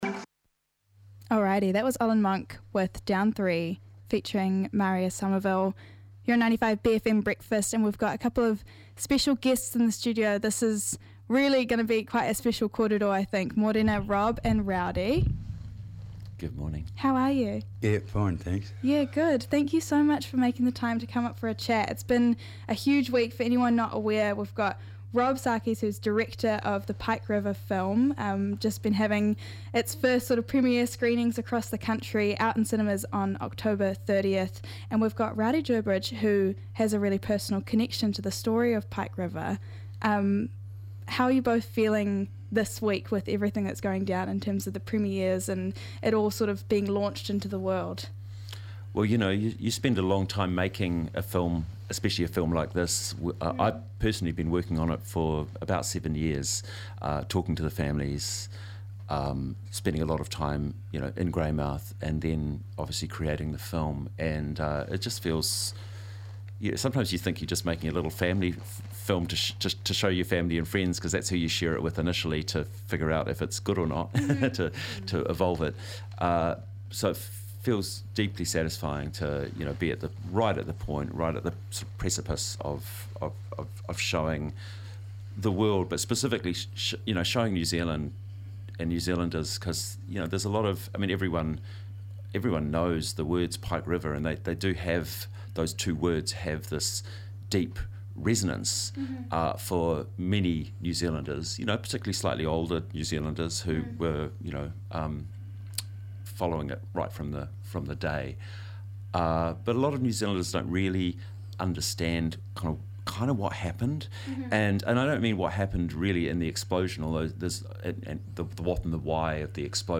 Guest Interview w